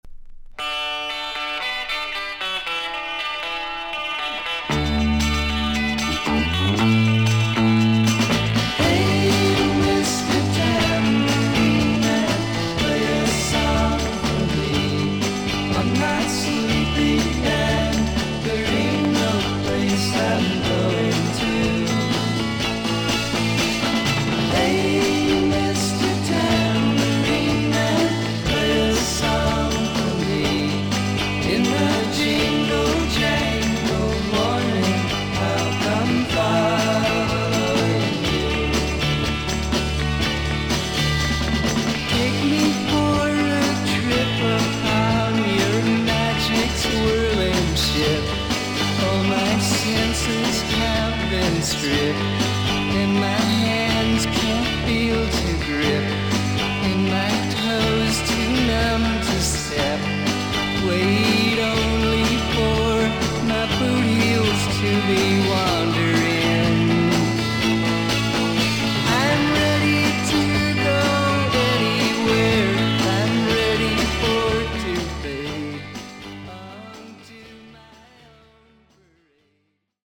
盤面に長短の浅いキズがありますが見た目ほどノイズはありません。
少々サーフィス・ノイズあり。クリアな音です。